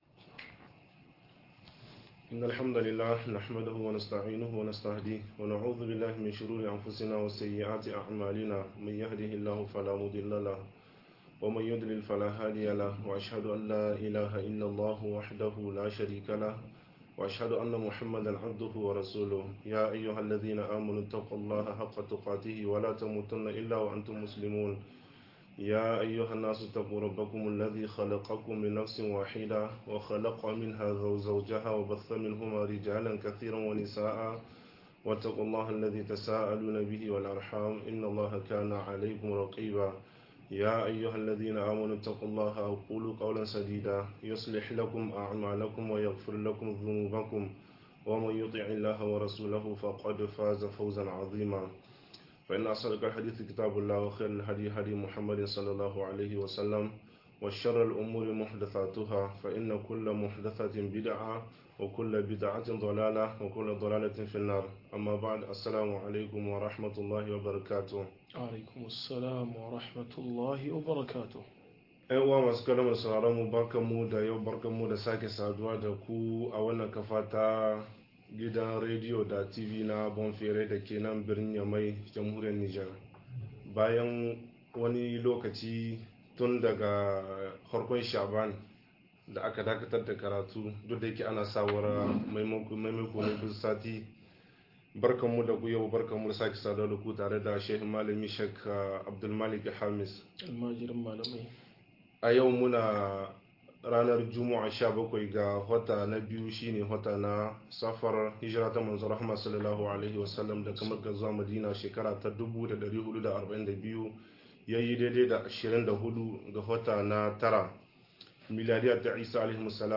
Saka hannun jari a Muslunci - MUHADARA